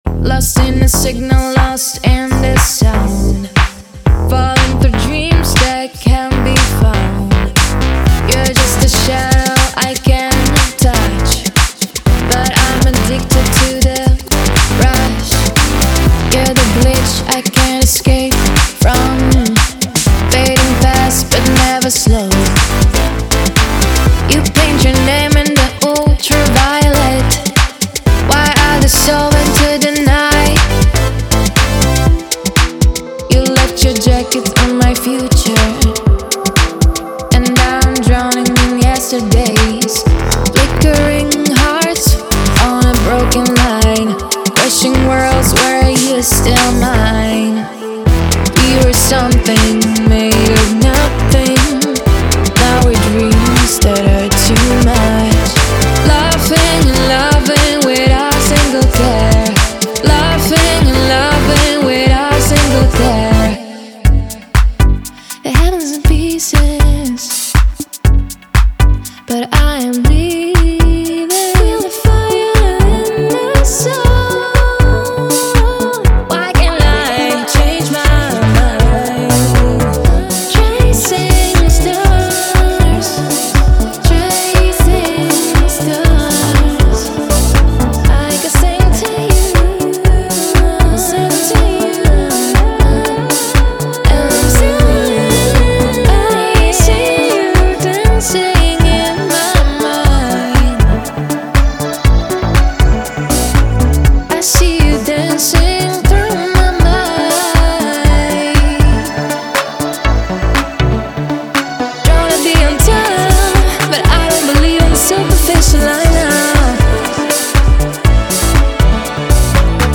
Genre:Pop
すべて120 BPMで、ヒット間違いなしのロイヤリティフリー・ボーカルをお届けします。
デモサウンドはコチラ↓
120 Female Vocals